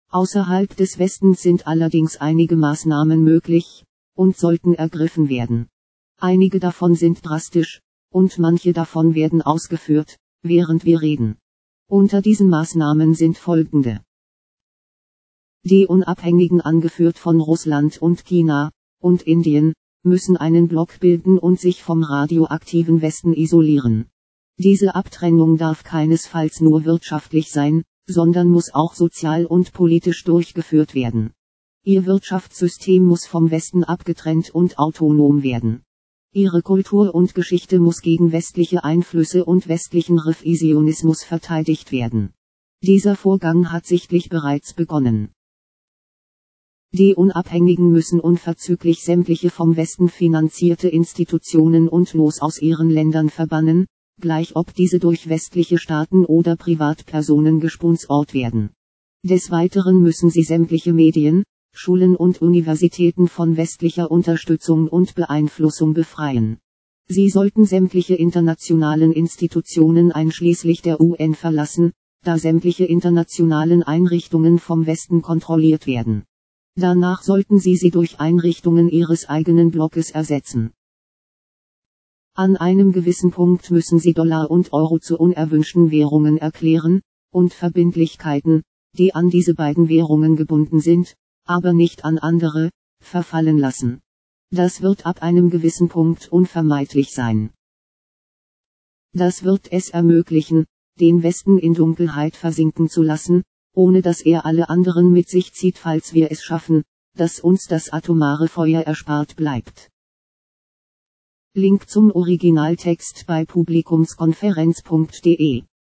...Artikel vorlesen  | Download / Popupfenster öffnen Teil 1  |